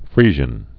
(frēzhən)